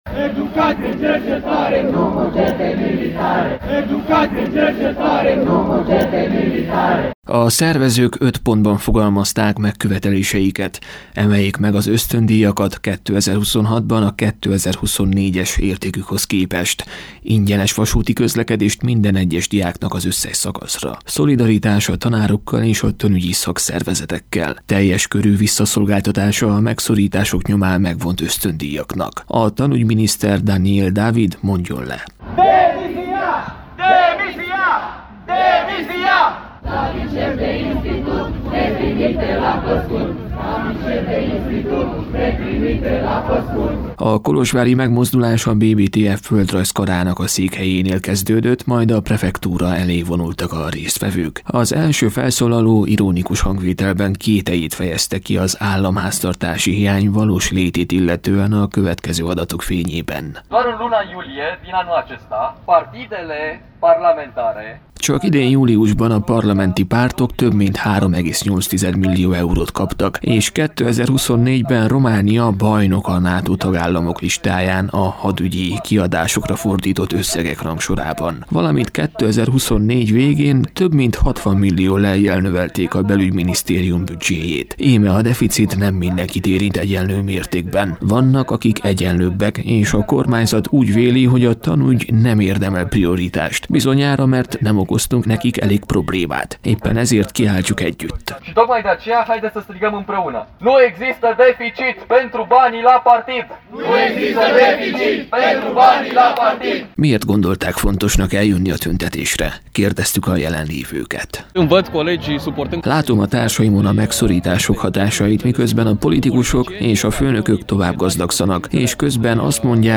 Az egyik vezetőjét is sikerült megszólaltatnunk, aki azonban nem vállalta a nevét.